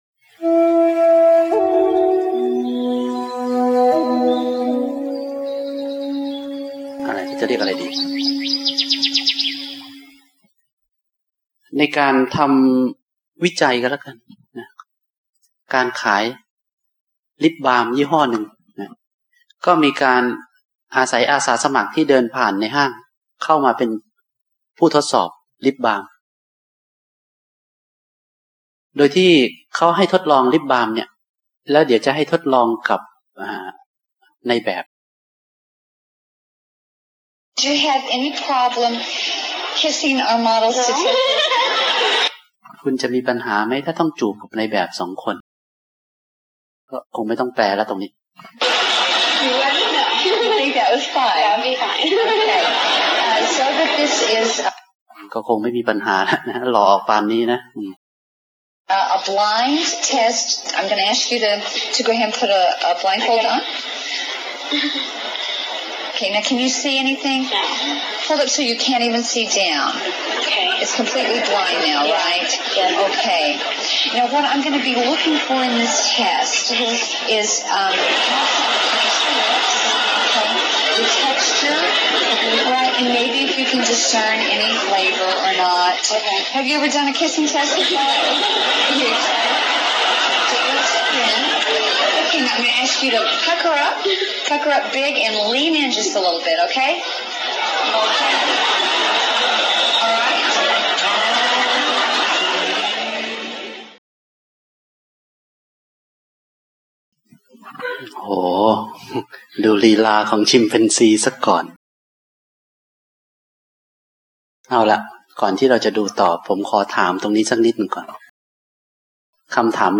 ธรรมบรรยายเสียง mp3 จากคอร์สปฏิบัติธรรม
บรรยายเมื่อวันที่ 9-15 มีนาคม 2556 ณ ยุวพุทธิกสมาคม